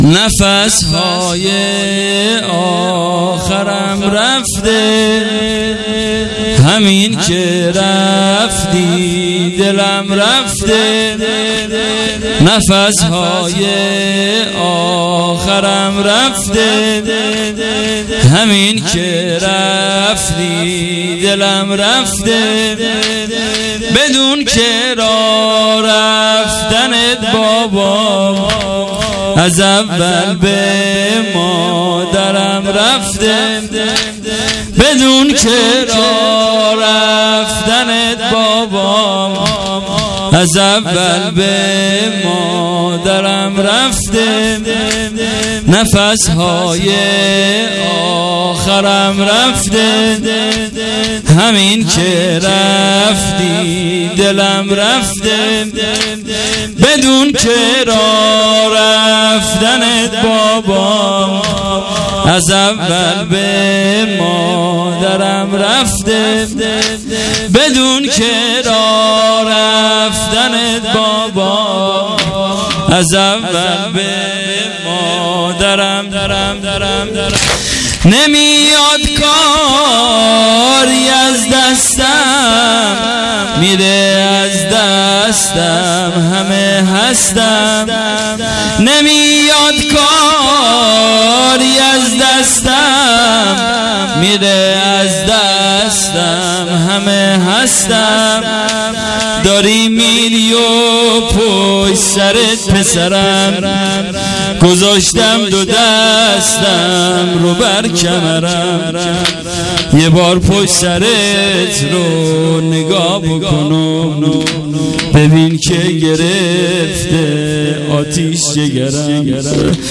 شب هشتم محرم الحرام ۱۳۹۹